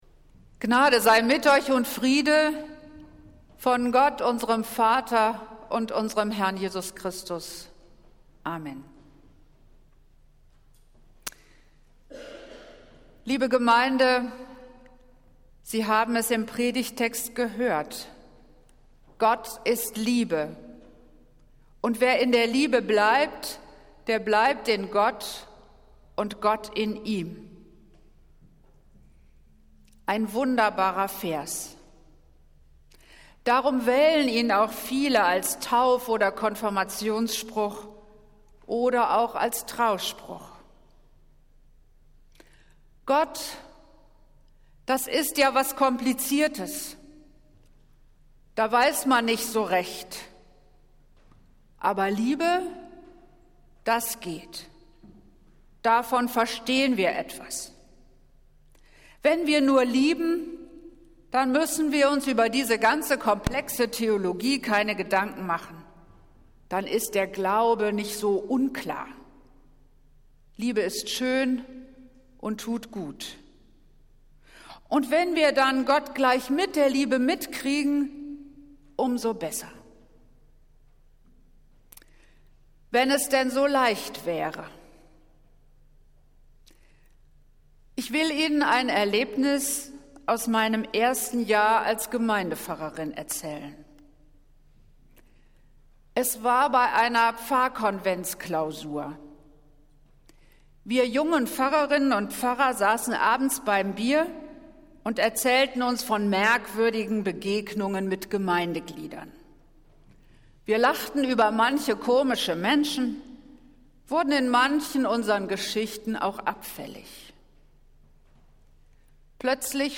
Predigt des Gottesdienstes aus der Zionskirche am Sonntag, den 11. Juni 2023